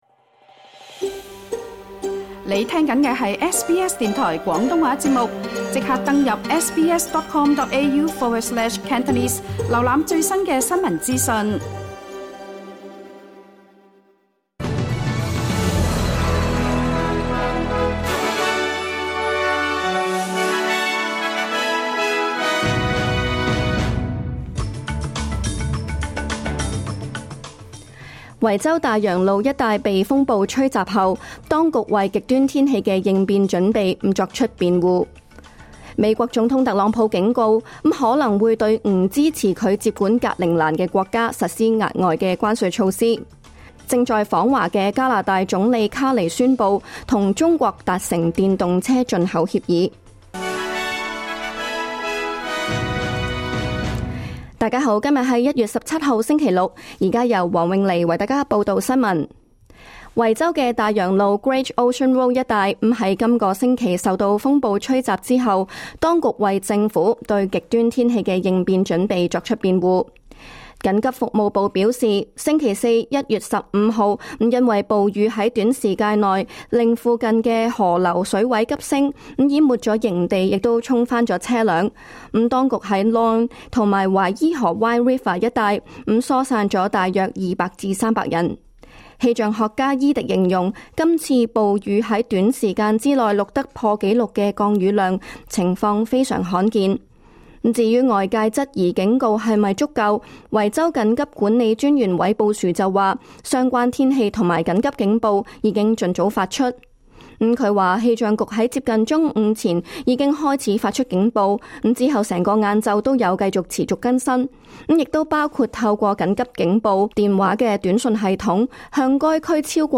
2026 年 1月 17日 SBS 廣東話節目詳盡早晨新聞報道。